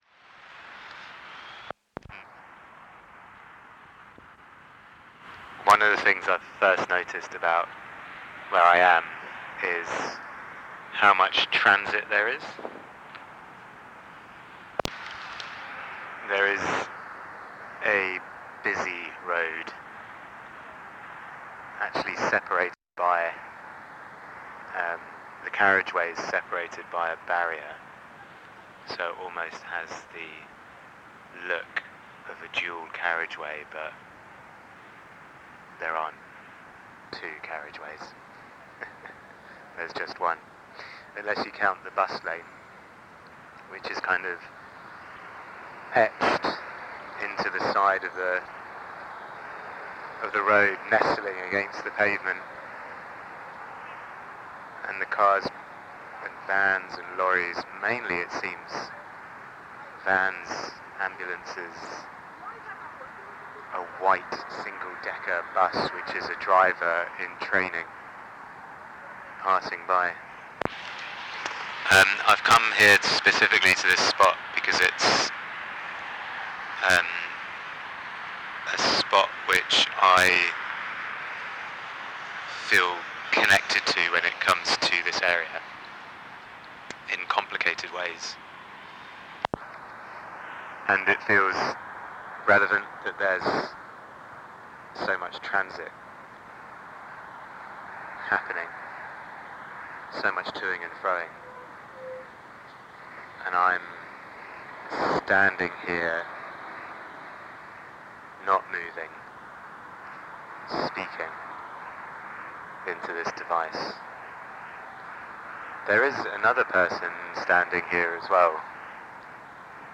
During the drafting process, the writers recorded voice letters to each other while at the four locations.
VOICE LETTER EXAMPLE